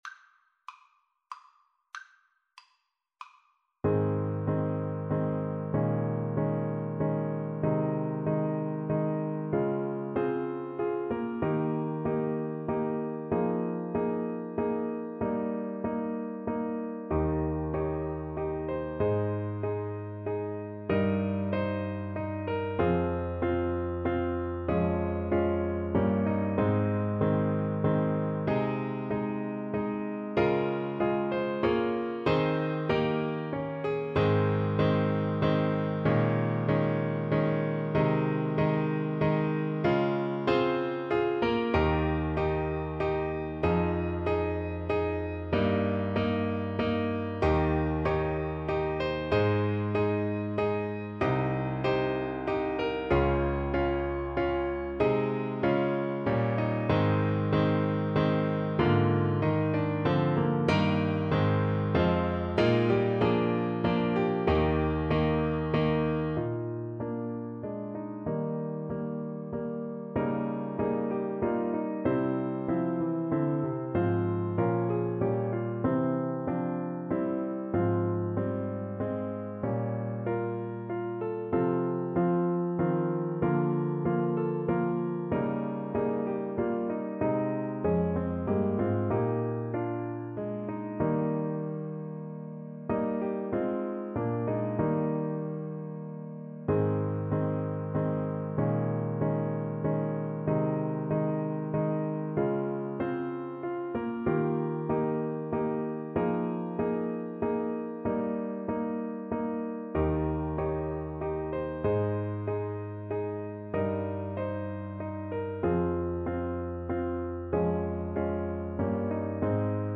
=95 Andante